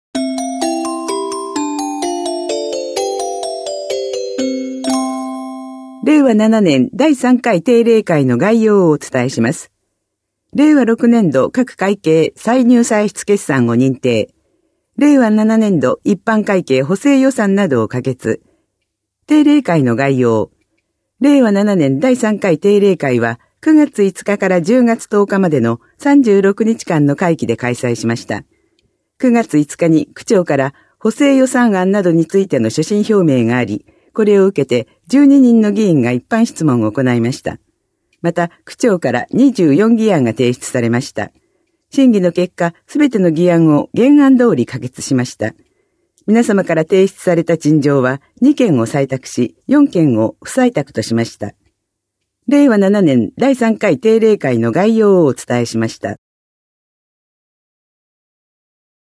声の区議会だより（音声データ）
練馬区議会では、目の不自由な方のために、デイジーによる「声の区議会だより」を発行しています。